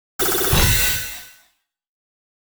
Upgrade Complete Sequence 1.wav